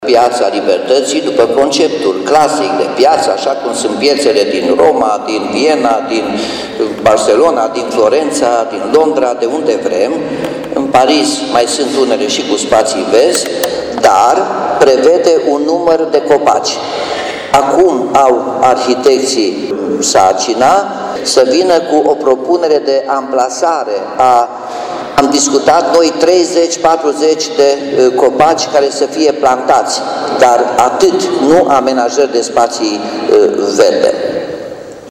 Noua amenajare din Piața Libertății, modernizată din bani europeni nu prevede niciun spațiu verde. Anunțul a fost făcut de primarul Nicolae Robu, care a spus că se caută soluții pentru plantarea a 30-40 de copaci în piață.